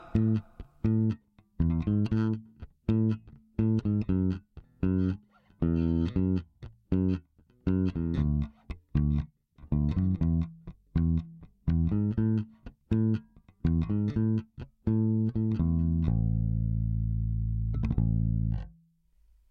ベースラインでこの曲なーんだ